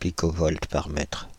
Prononciation
Prononciation France (Île-de-France): IPA: /pi.kɔ.vɔlt paʁ mɛtʁ/ Le mot recherché trouvé avec ces langues de source: français Traduction Contexte Substantifs 1.